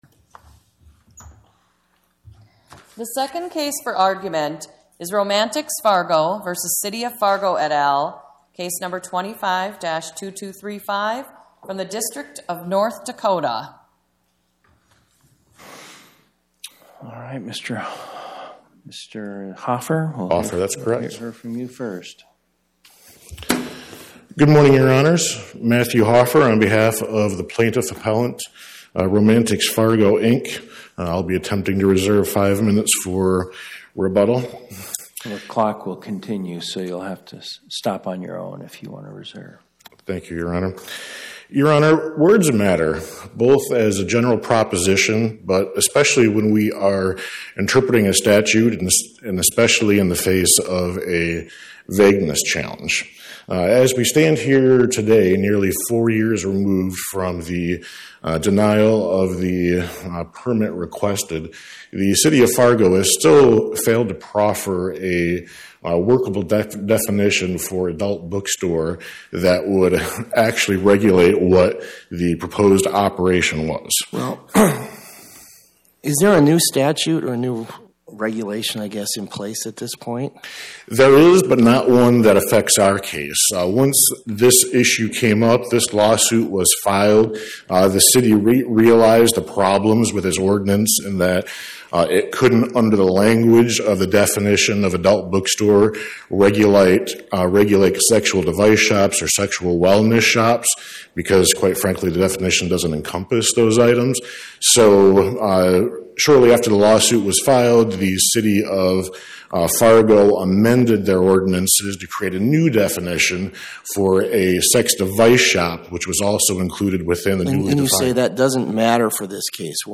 My Sentiment & Notes 25-2235: Romantix-Fargo, Inc. vs City of Fargo Podcast: Oral Arguments from the Eighth Circuit U.S. Court of Appeals Published On: Tue Mar 17 2026 Description: Oral argument argued before the Eighth Circuit U.S. Court of Appeals on or about 03/17/2026